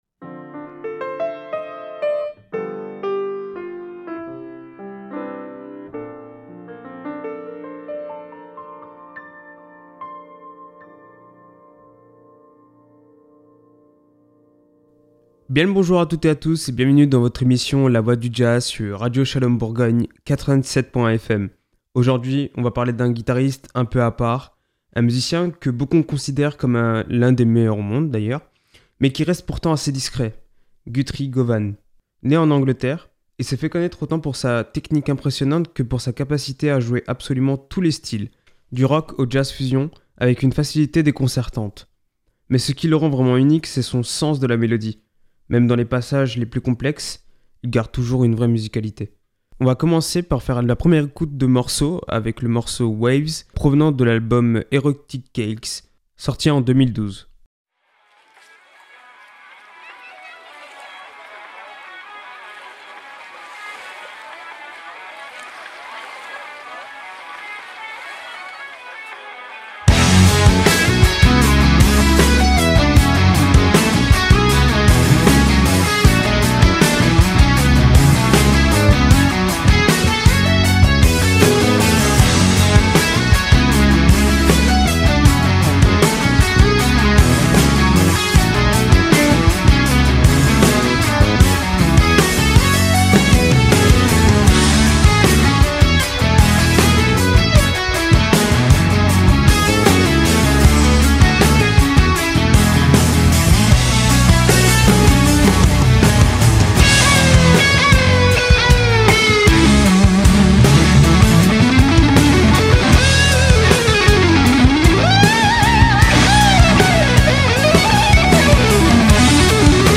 Un voyage sonore entre finesse, groove et maîtrise.